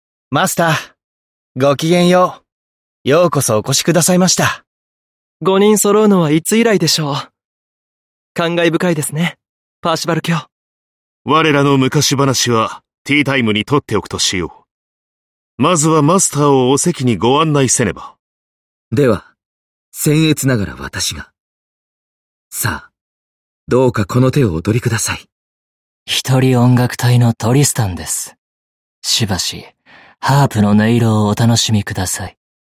声优 松风雅也&宫野真守&置鲇龙太郎&水岛大宙&内山昂辉